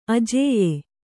♪ ajēya